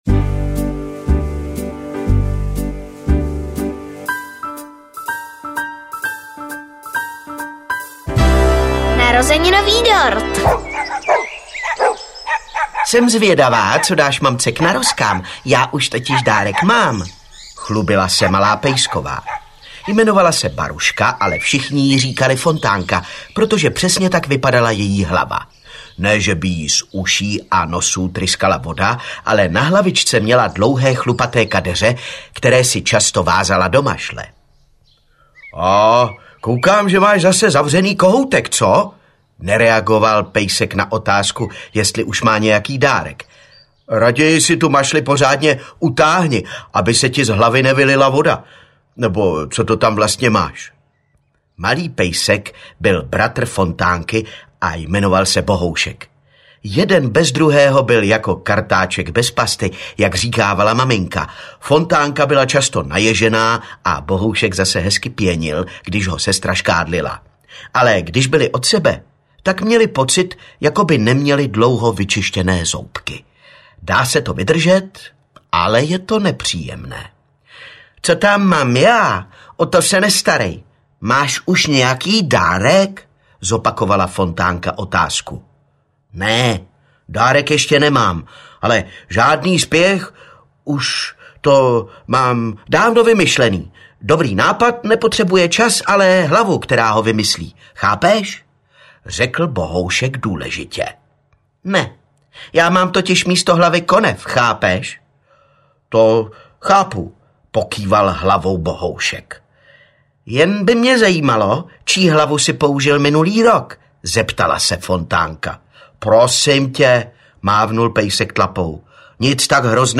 Animálci aneb Jak se směje les audiokniha
Ukázka z knihy